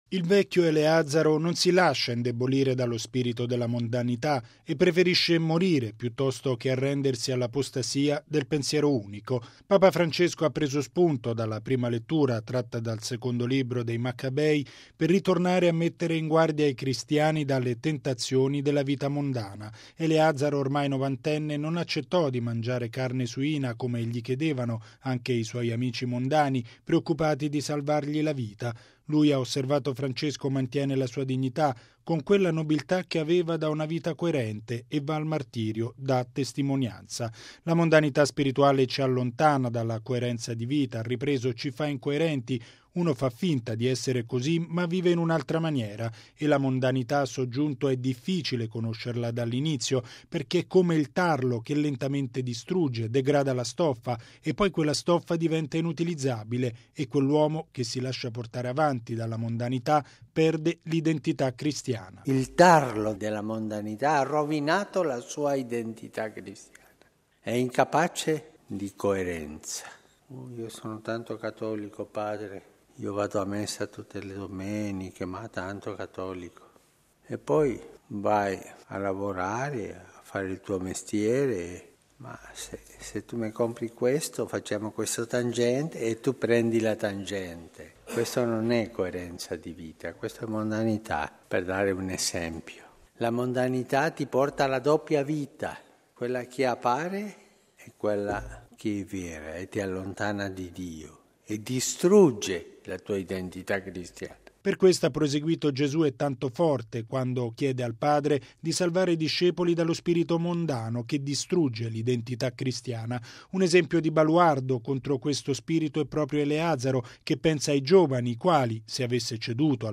E’ il monito di Papa Francesco nella Messa mattutina a Casa Santa Marta. Il Pontefice ha ribadito che, per custodire l’identità cristiana, bisogna essere coerenti ed evitare le tentazioni di una vita mondana.